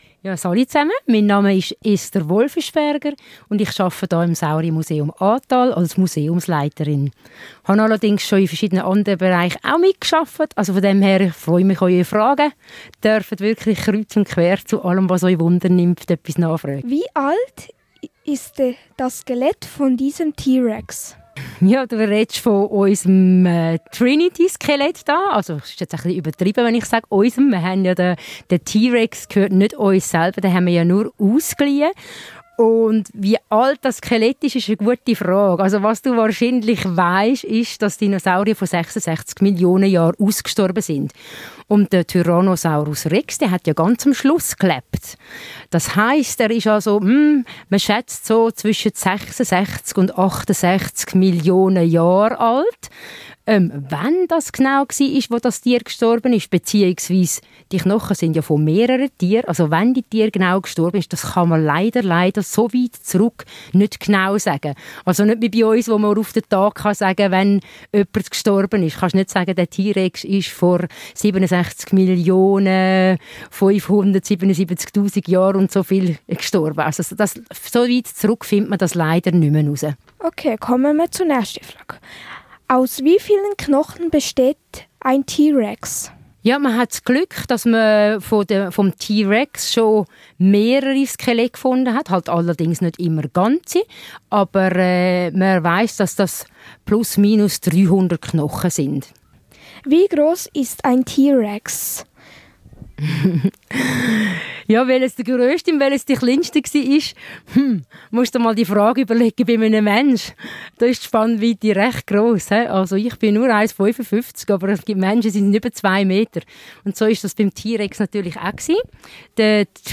Für diesen Radiobeitrag war ich zu besuch, im Dinosauriermuseum im Aatal. Zurzeit ist dort nämlich ein T.Rex mit echten Skeletteile ausgestellt.